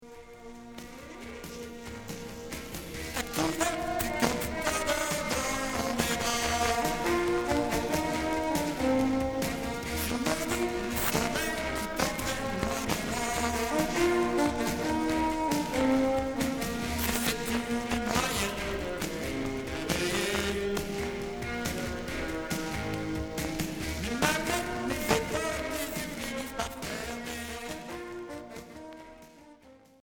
Rock pop